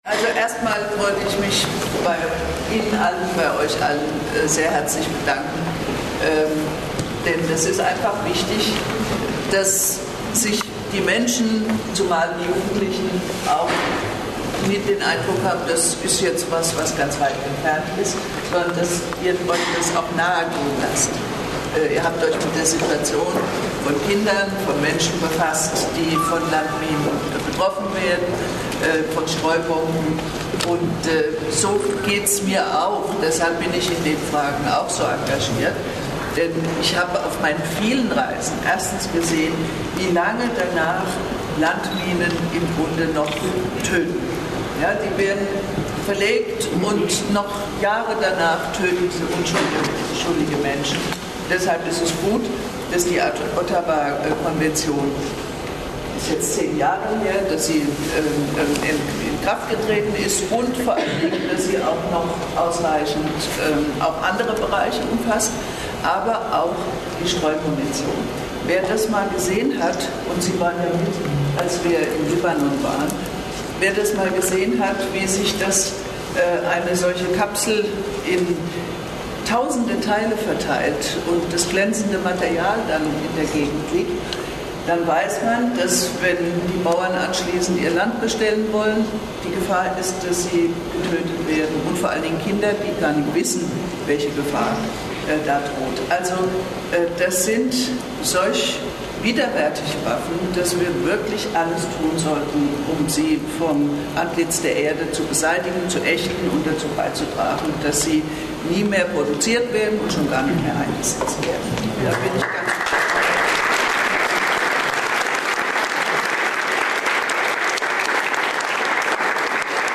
Die Rede von Bundesministerin Heidemarie Wieczorek-Zeul vom 03.12.2007 steht als WMV-Video und als MP3-Audio zur Verfügung, in Kürze wird ein Videozusammenschnitt der Aktion im Bundesministerium zum Download hier erhältlich sein: